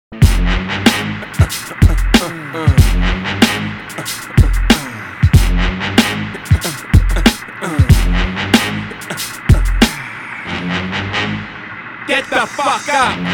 • Качество: 320, Stereo
Хип-хоп
Bass
качает
Для звонка! Почти без слов!)